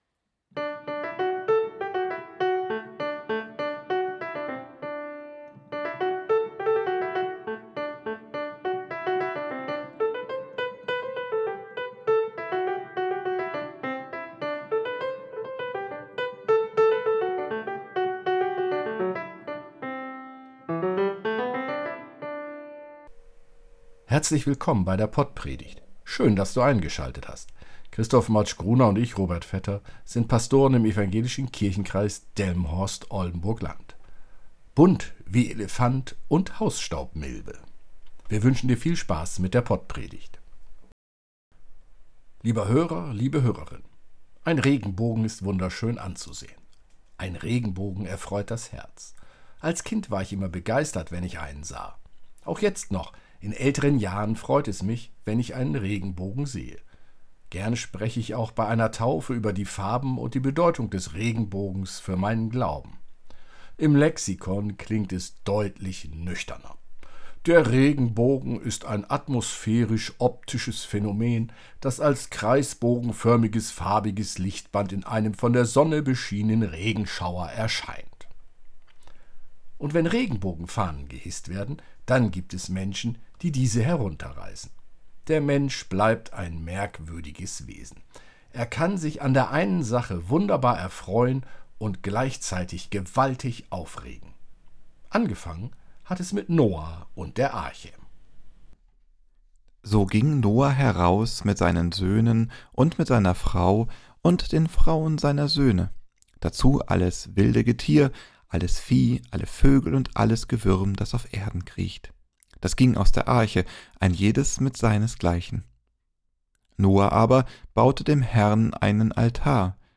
PodPredigt